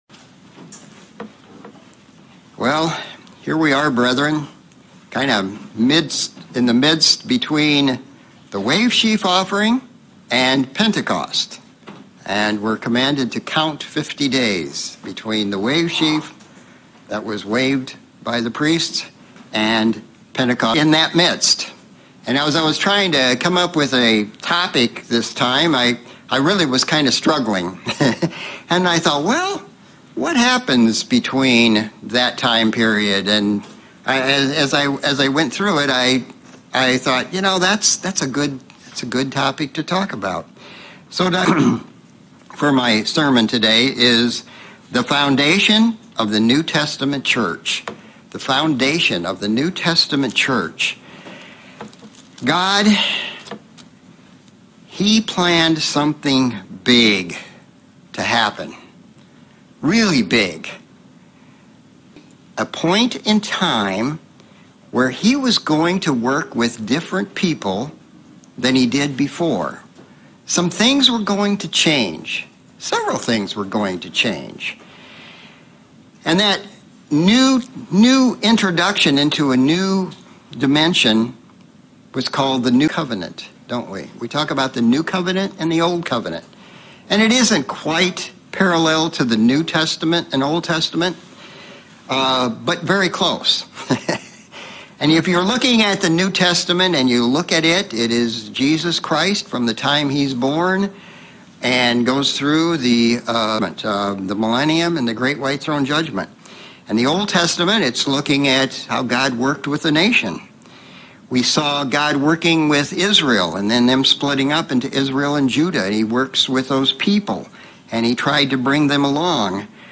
Sermon looking at the work Christ did with the disciples during his last 40 days on earth and preparing them for the foundation of the New Testament chruch.